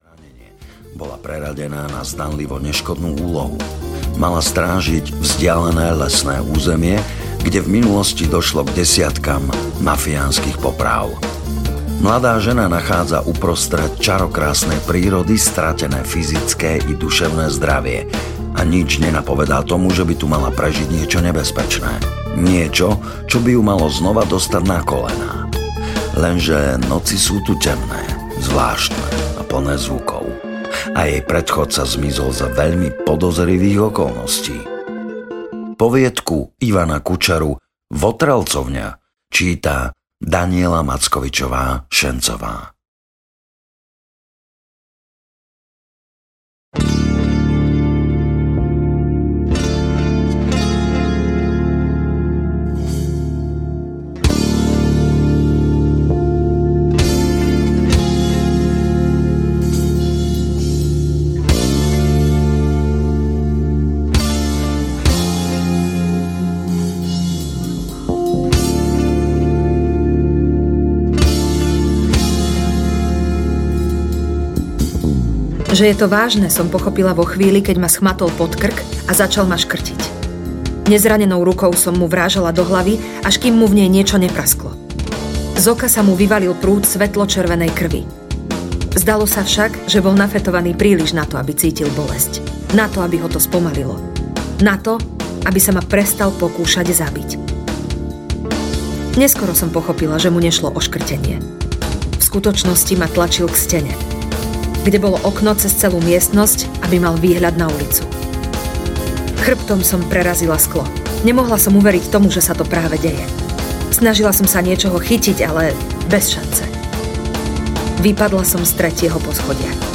Ukázka z knihy
Ide o vôbec prvú stereo audioknihu na Slovensku.„My naozaj chceme, aby to tí ľudia hrali.
Tejto role sa úspešne zhostil Robo Roth, ktorý vás svojím hlbokým hlasom prevedie z príbehu do príbehu.Hudba: Hudba sprevádza každé jedno slovo v audioknihe.